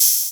CR8000Hat_O.wav